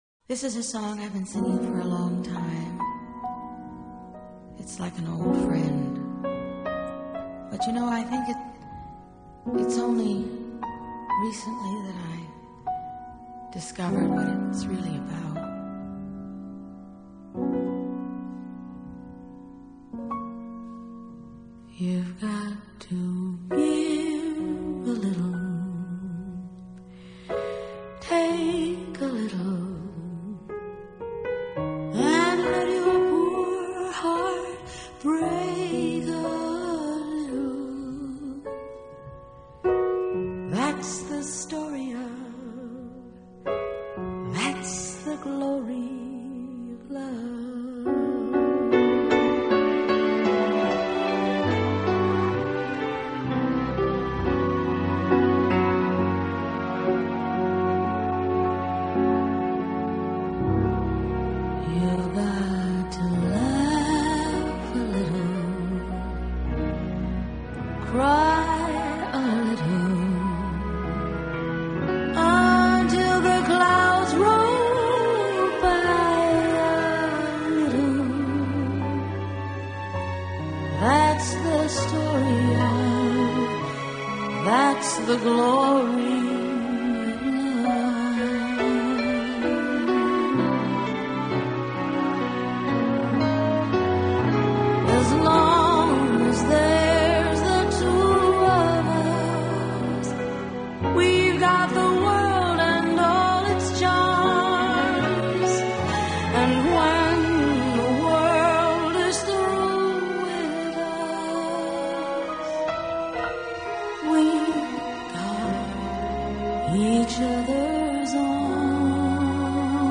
Main theme